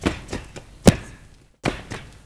FOOTWORK  -R.wav